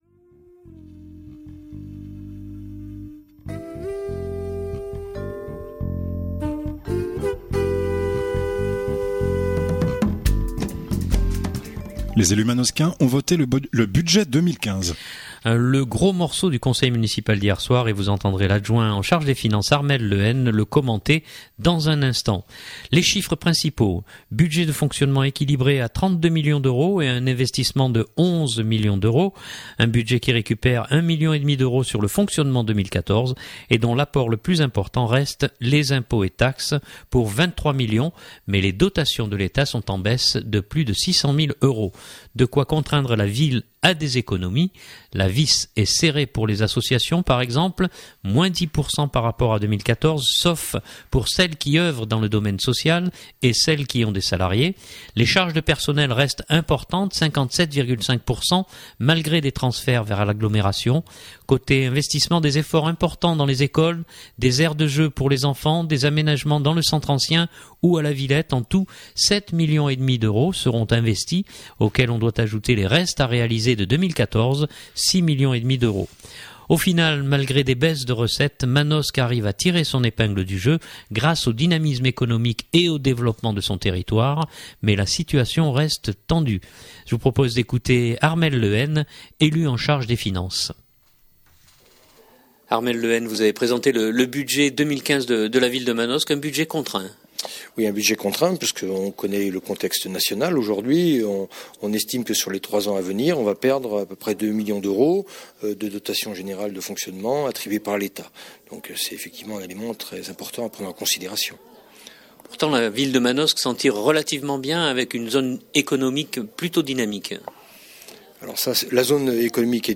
Le gros morceau du conseil municipal d’hier soir et vous entendrez l’adjoint en charge des finances Armel Le Hen le commenter dans un instant. Les chiffres principaux : budget de fonctionnement équilibré à 32 M€ et un investissement de 11 M€.
Au final malgré des baisses de recettes, Manosque arrive à tirer son épingle du jeu, grâce au dynamisme économique et au développement de son territoire mais la situation reste tendue. Je vous propose d’écouter Armel Le Hen élu en charge des finances.